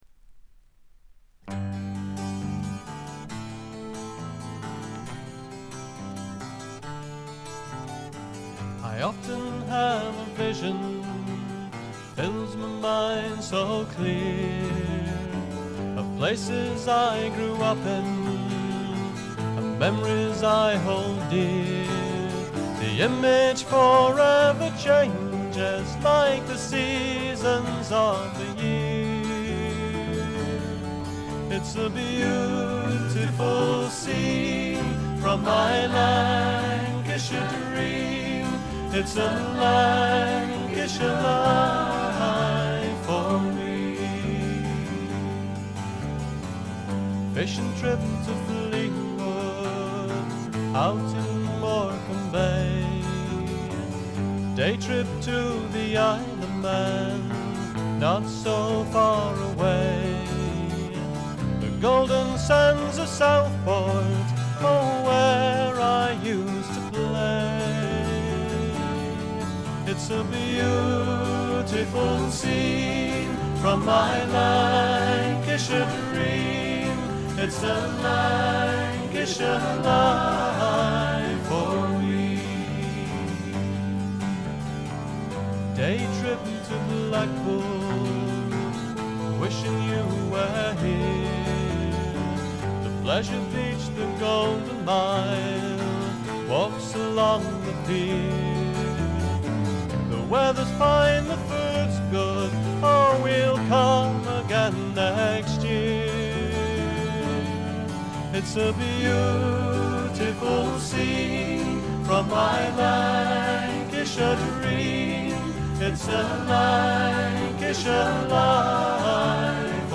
部分試聴ですがチリプチ、プツ音少々。
男女ヴォーカルでいたってフツーのフォークを演っていますが、こういうのもいいもんです。
試聴曲は現品からの取り込み音源です。